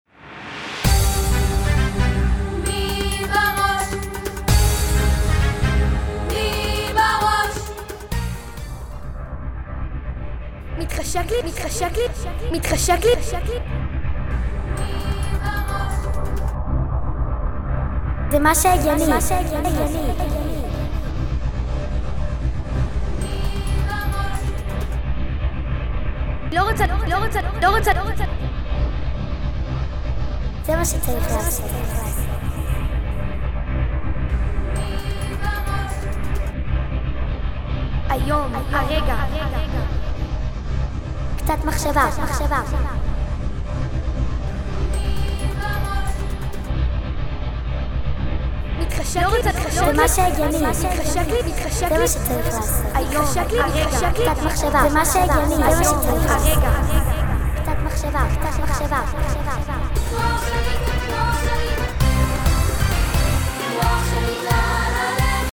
שיר